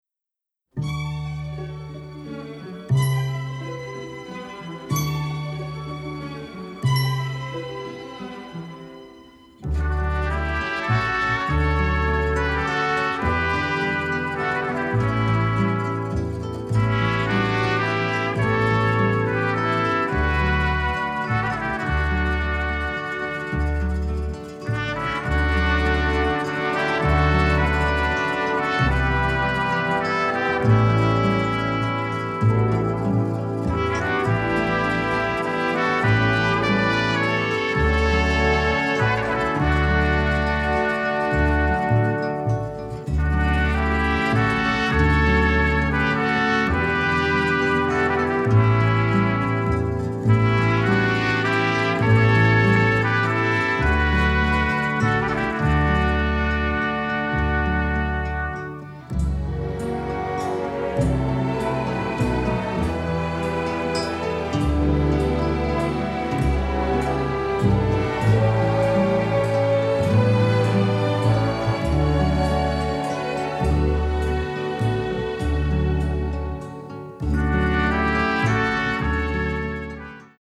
catchy, lovely score